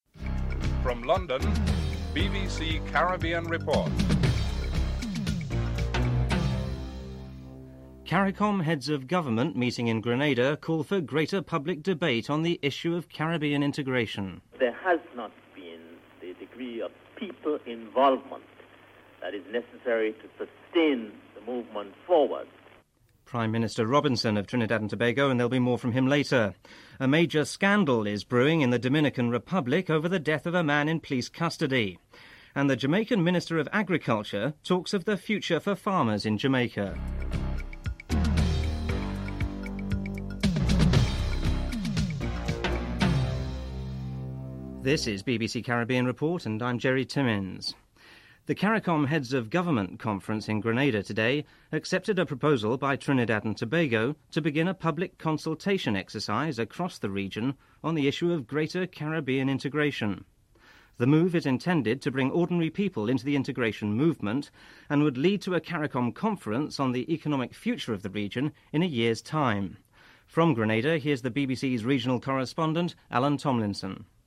1. Headlines (00:00-00:42)
Horace Clarke, Jamaica's Minister of Agriculture is interviewed in Britain (07:46-11:03)